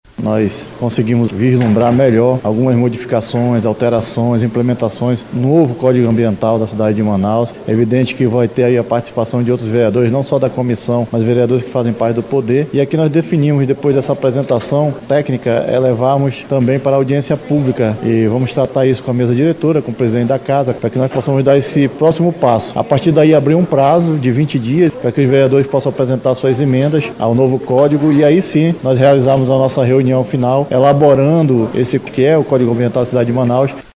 O presidente da Comissão, Joelson Silva explica quais serão as próximas etapas.